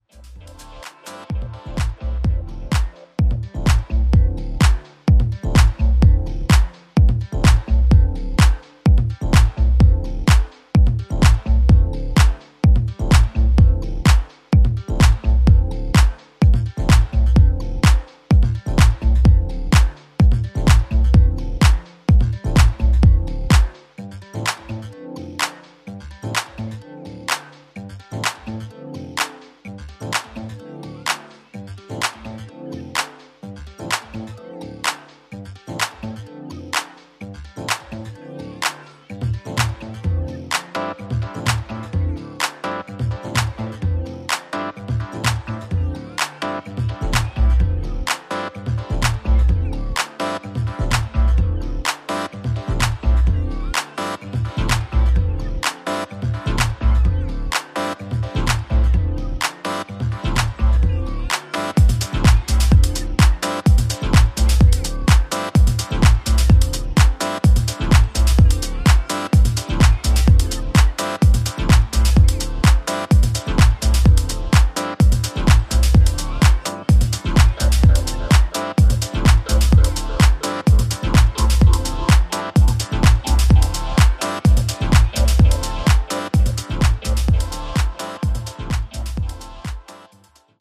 ジャンル(スタイル) TECH HOUSE